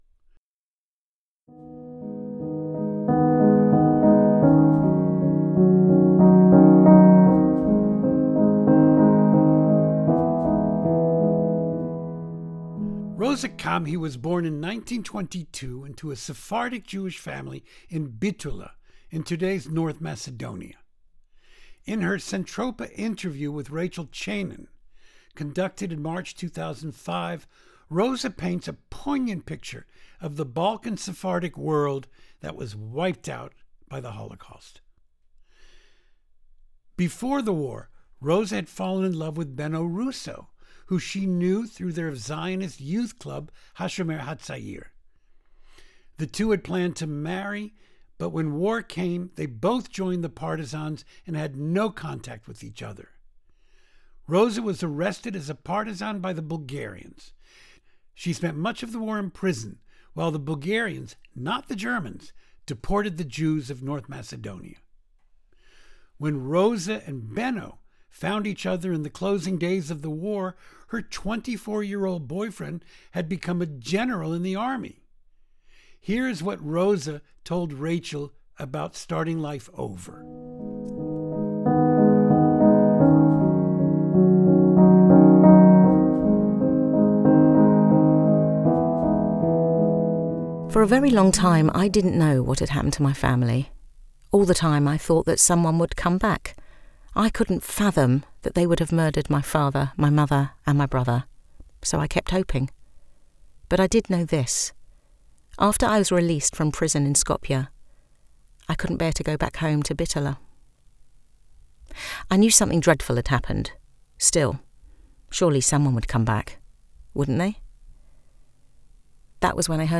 We have translated and edited them and they are read for us by actors in London.